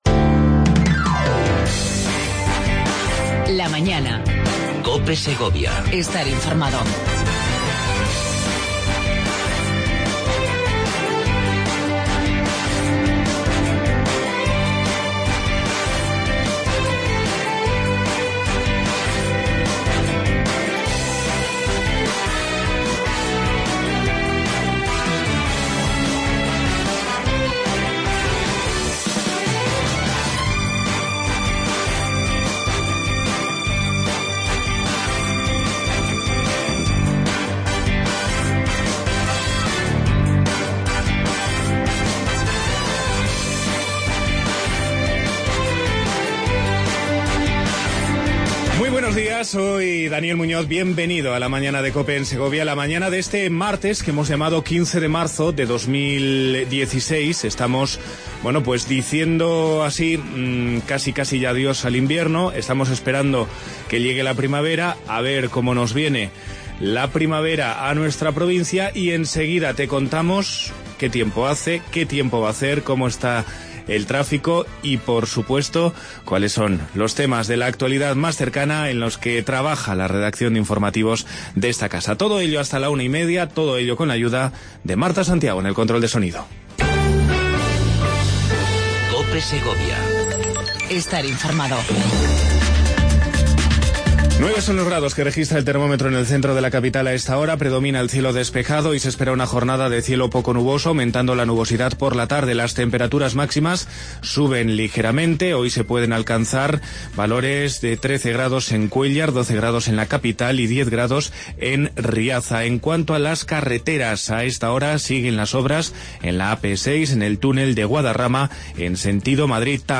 AUDIO: Entrevista con Clara Luquero, alcaldesa de Segovia.